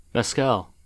Mezcal (/mɛˈskæl/
En-us-mezcal.oga.mp3